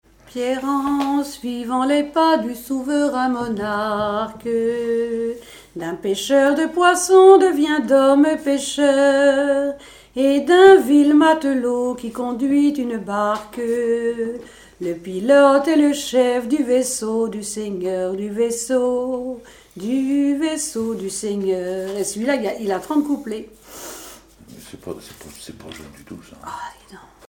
circonstance : cantique
Genre strophique
Chansons et témoignages de pêche sur les voiliers
Pièce musicale inédite